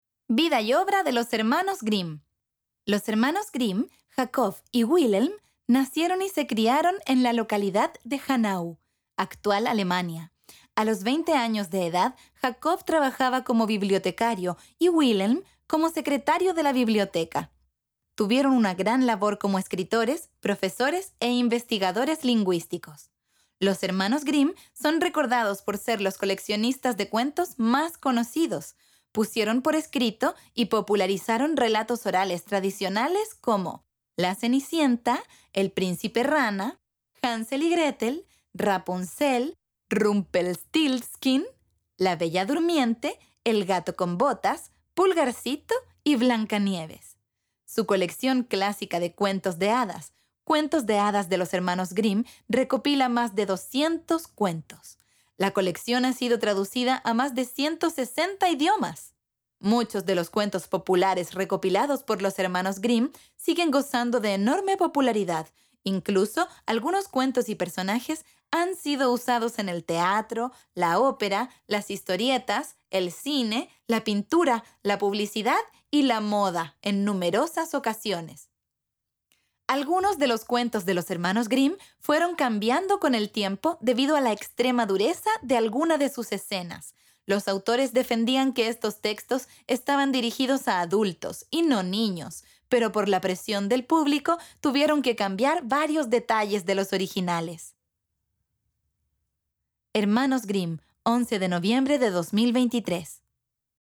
Audiolibro
Audiolibro que nos cuenta de la vida y obra de los hermanos Jacob y Wilhelm Grimm, los célebres recopiladores de los cuentos populares más conocidos del mundo. Desde su infancia en Alemania hasta su trabajo como lingüistas, académicos y defensores del folclore.